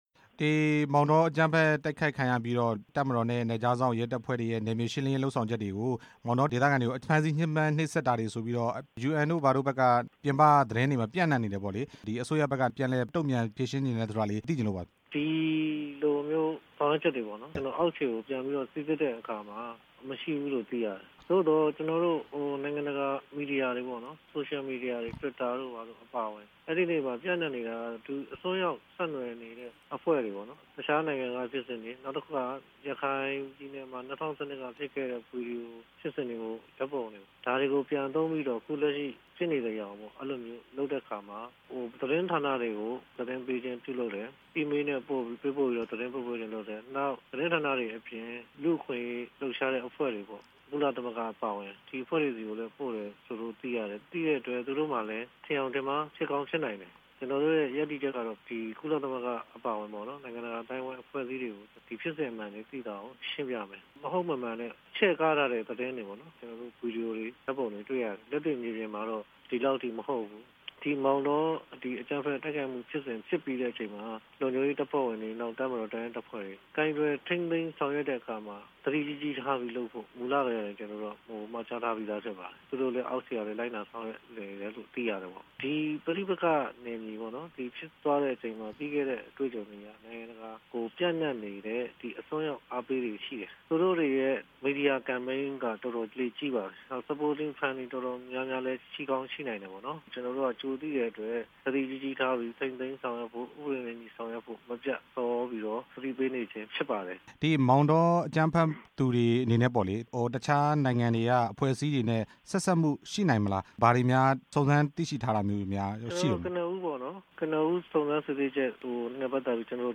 နိုင်ငံတော်သမ္မတရုံး ပြောရေးဆိုခွင့်ရှိသူ ဦးဇော်ဌေး နဲ့ မေးမြန်းချက်